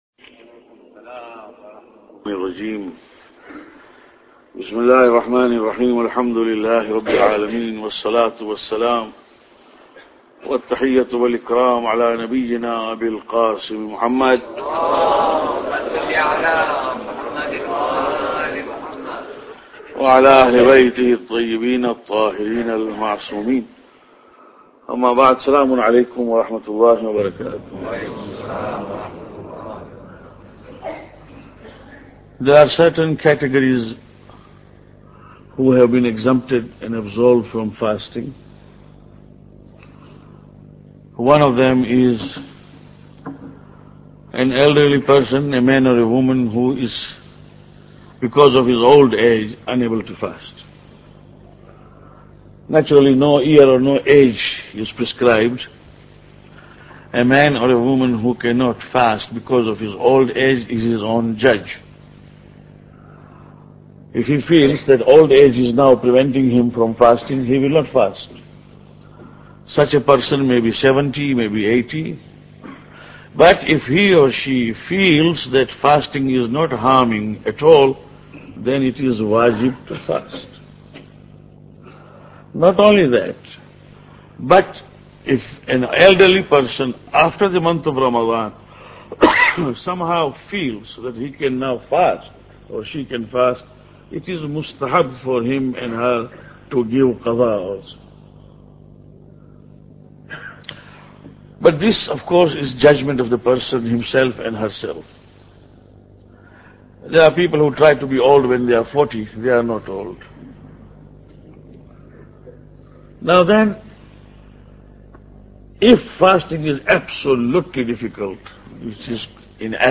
Lecture 7